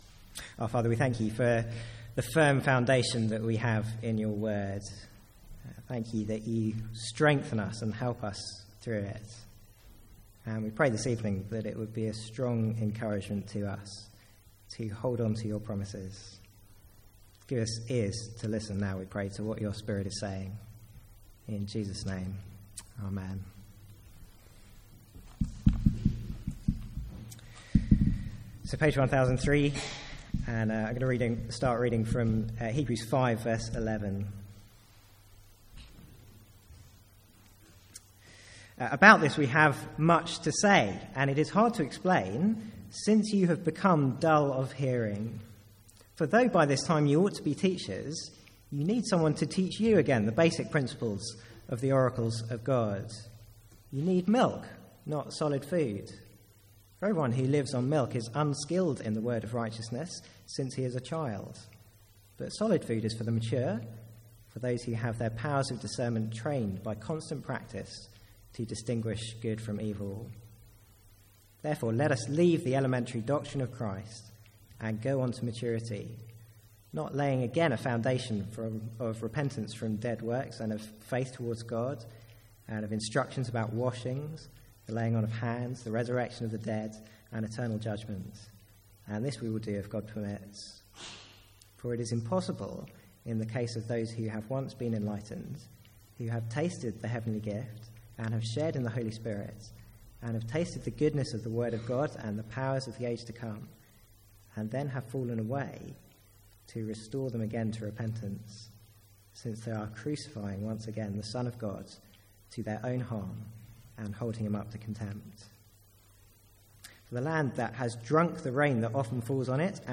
Sermons | St Andrews Free Church
From the Sunday evening series in Hebrews.